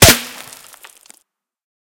bulletFlyBy_5.ogg